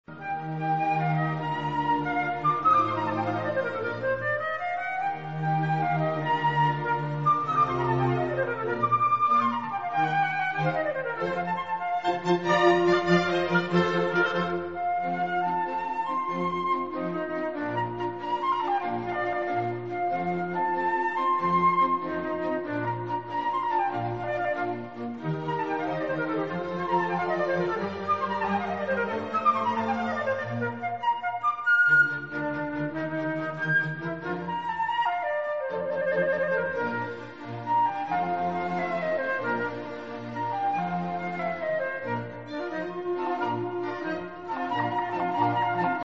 flute
"3 concertos"